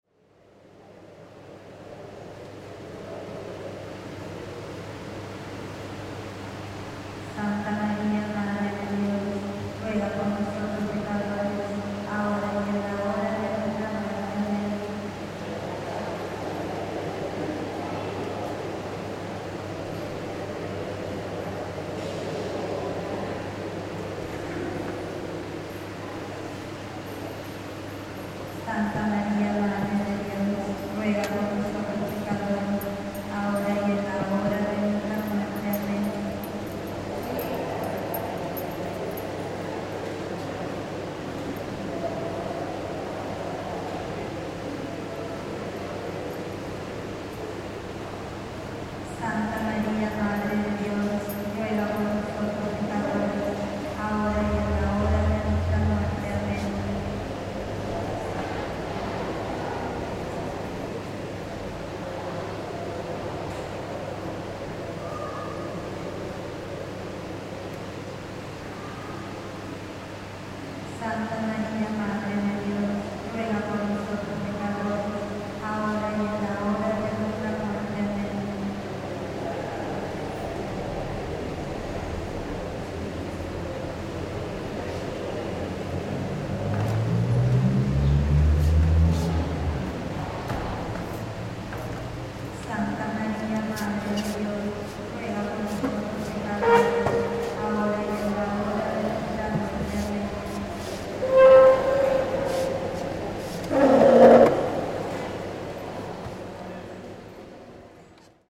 Religión. Registros 01